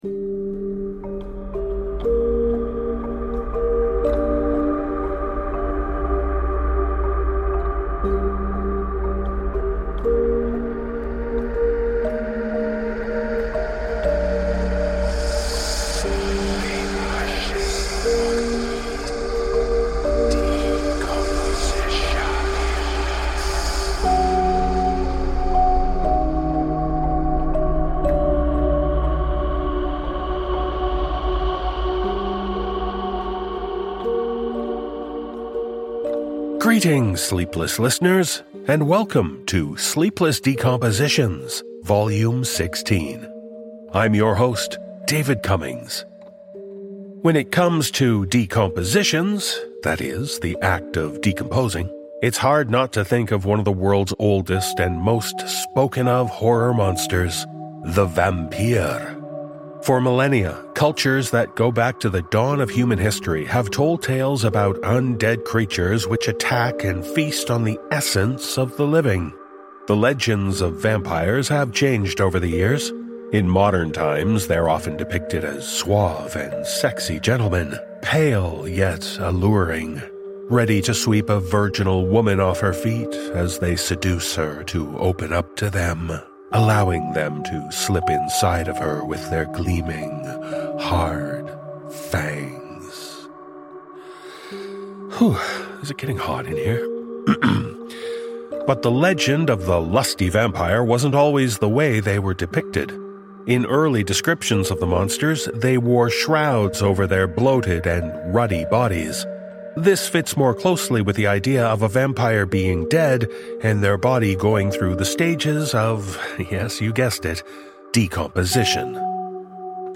Cast: Narrator
Musical score